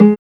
4305L GTRTON.wav